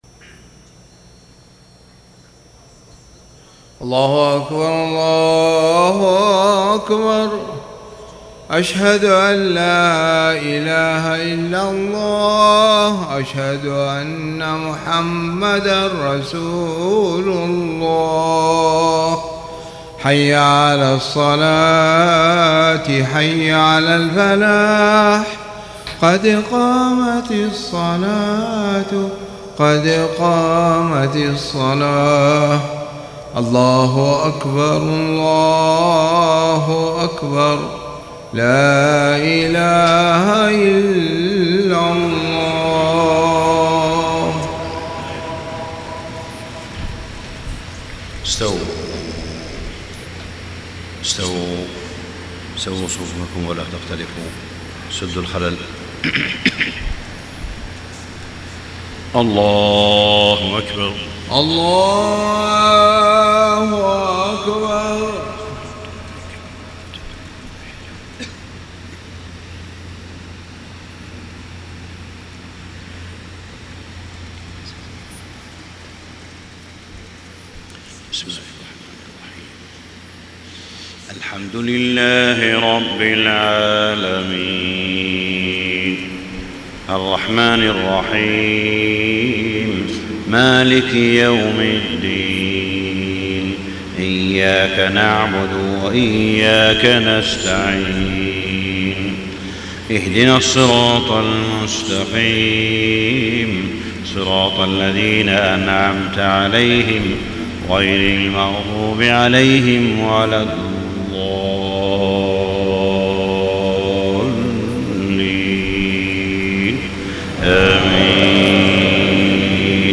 صلاة الفجر 4-9-1434 سورة الصف > 1434 🕋 > الفروض - تلاوات الحرمين